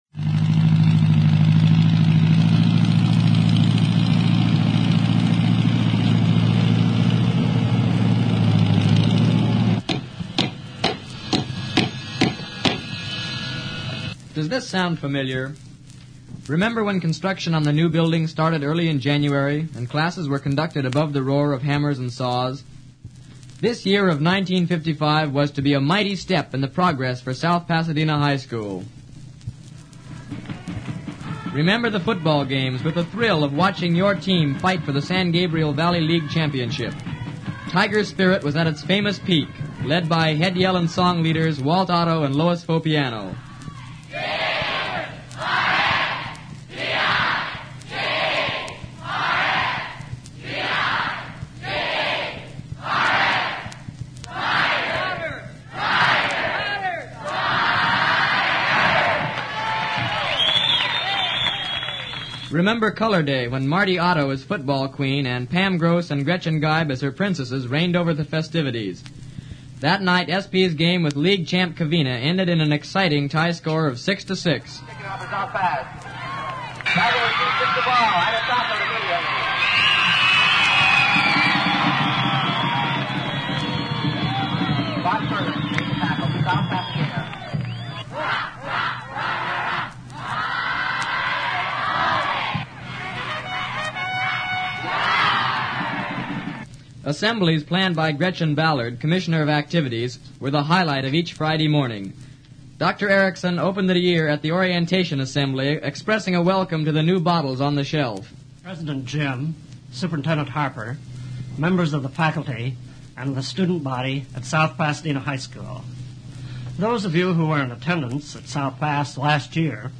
The 1955 Copa yearbook contained a 45 RPM recording of campus sounds and events for the year.
sphs 1955 annual campus sounds.mp3